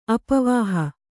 ♪ apavāhi